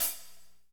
Index of /90_sSampleCDs/Northstar - Drumscapes Roland/DRM_Pop_Country/HAT_P_C Hats x
HAT P C L08L.wav